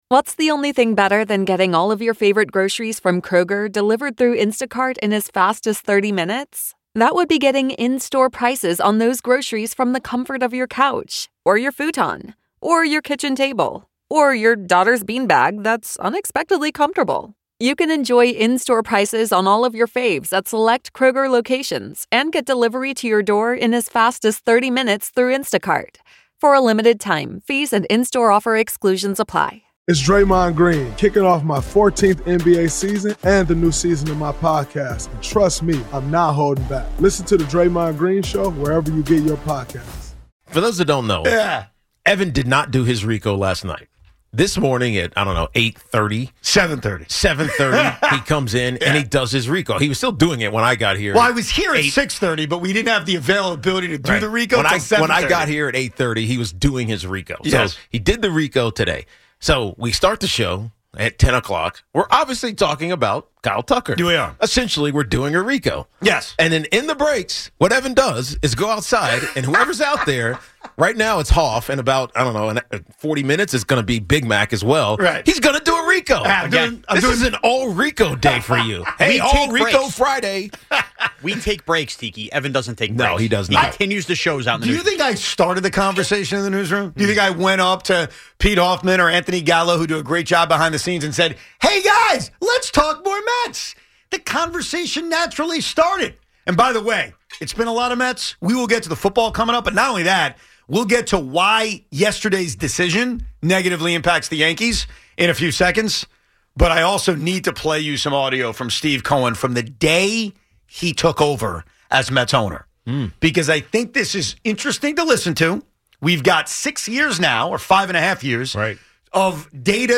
Audacy Sports